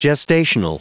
Prononciation du mot gestational en anglais (fichier audio)
Prononciation du mot : gestational